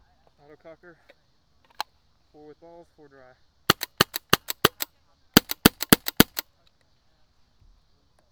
autococker_raw_minclip_01.wav